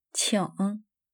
「qing」の発音をするポイント
qi」と「ng」を2つの音に分けて発音すること!
チン